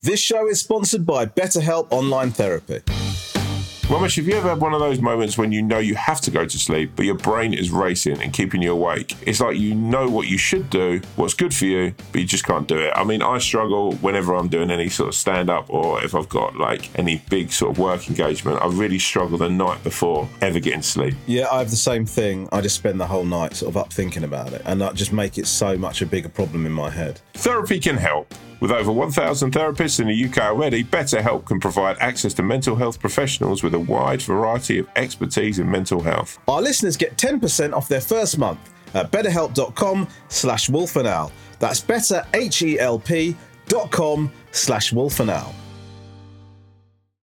VOICEOVERS
Voiceover-Romesh-Ranganathan.m4a